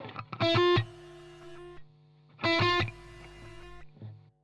Tie prve dva impulzy maju takyto reverb v sebe